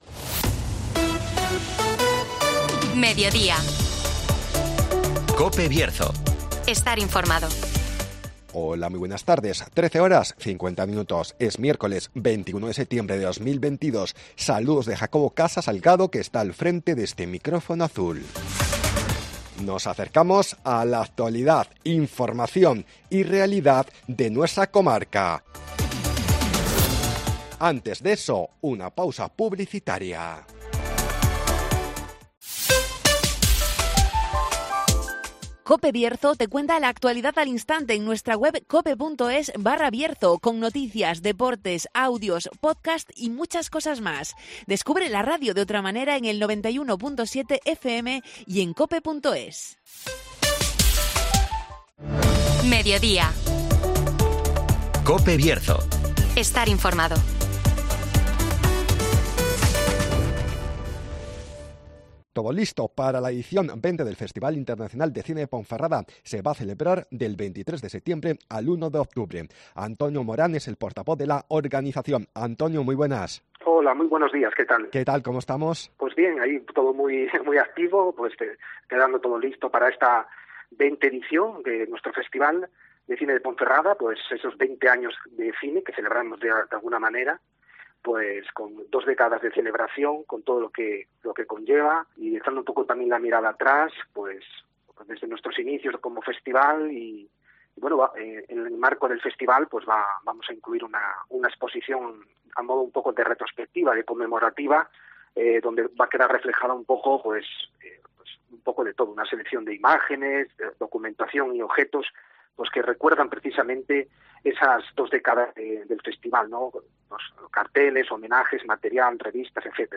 Todo listo para la edición 20 del Festival Internacional de Cine de Ponferrada (Entrevista